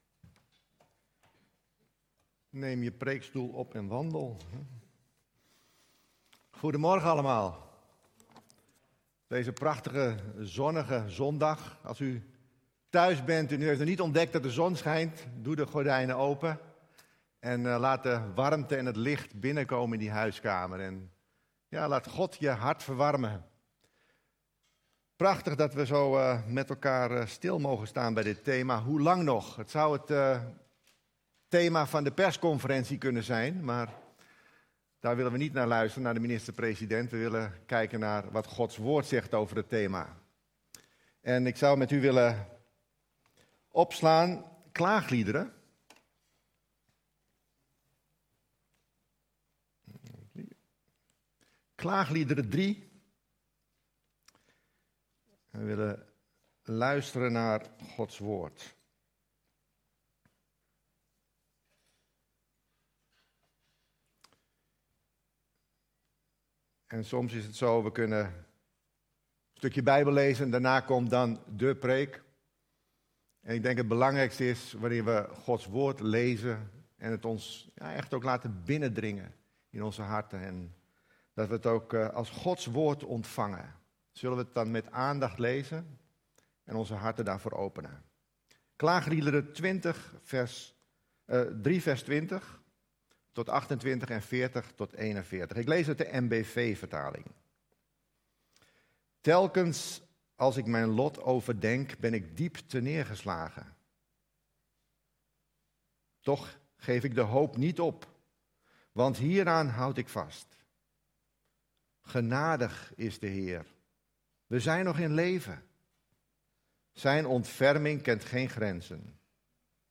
Toespraak 18 april: Hoe lang nog?